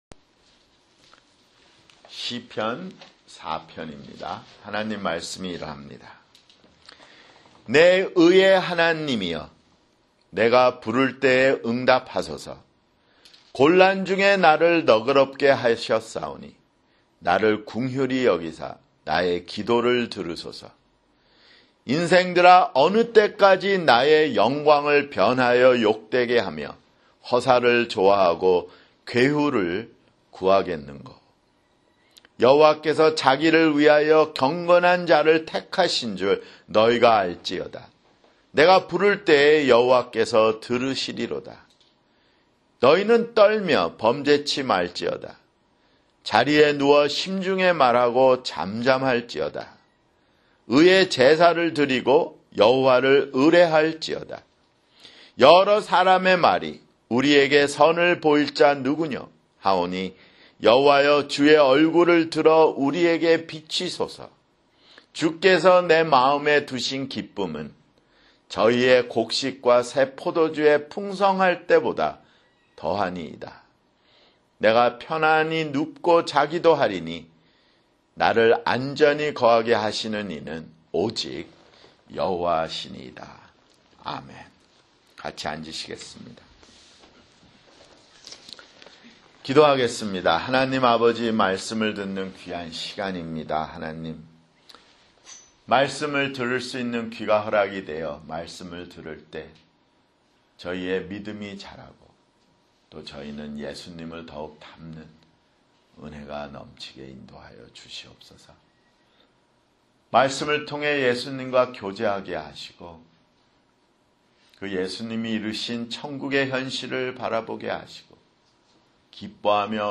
[주일설교] 시편 (5)